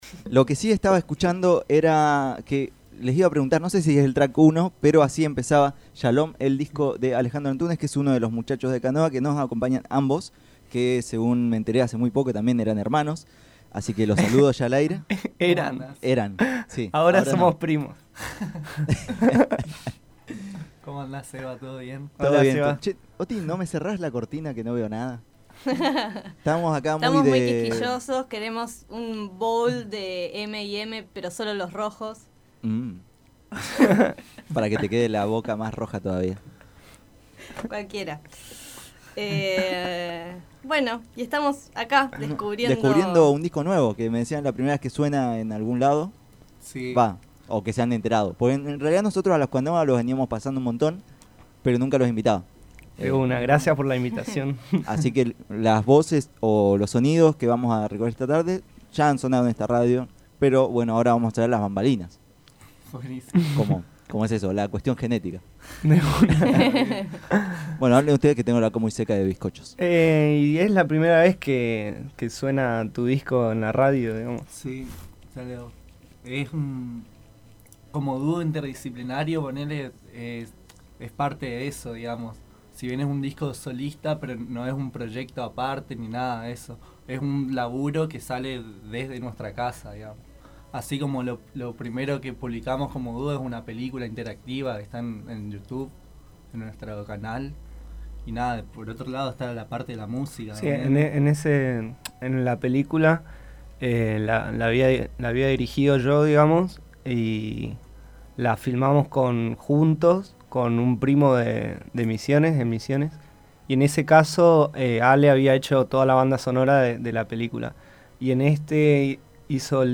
dúo interdisciplinario oriundo de Misiones, quienes visitaron Tren Para Pocos para contar el presente y futuro de sus producciones.